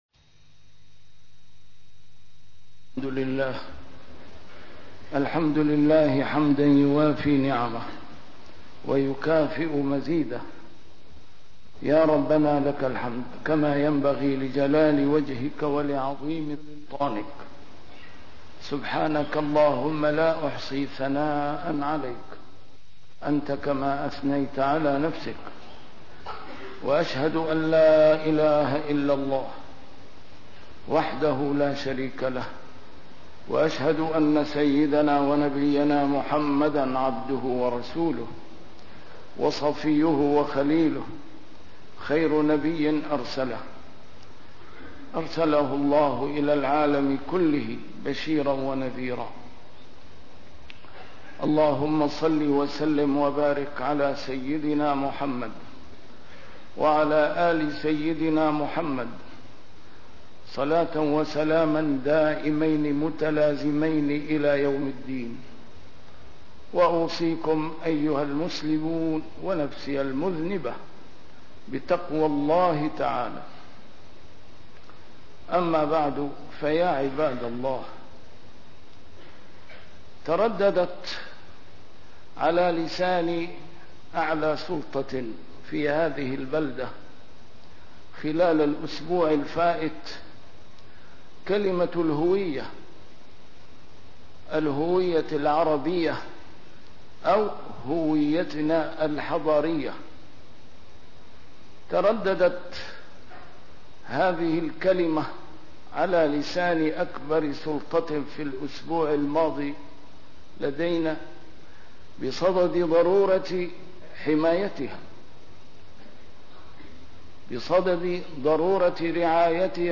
A MARTYR SCHOLAR: IMAM MUHAMMAD SAEED RAMADAN AL-BOUTI - الخطب - الحفاظ على هوية الأمة